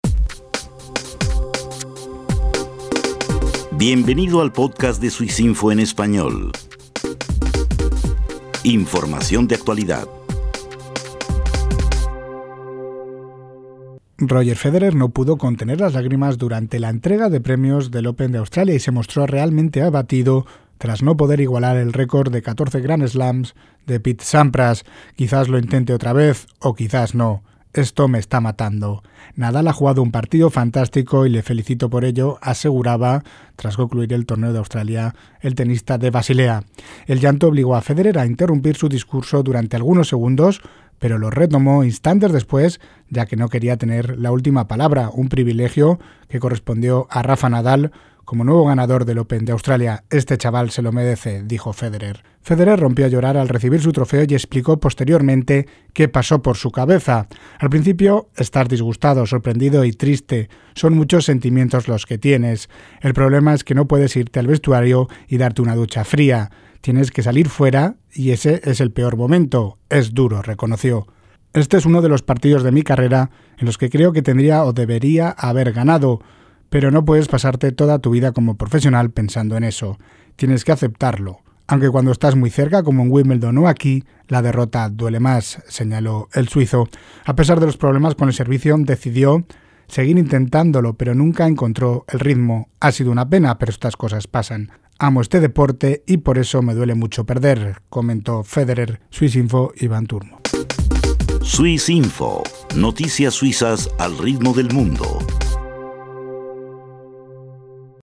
El suizo no pudo contener las lágrimas tras perder la final de Australia.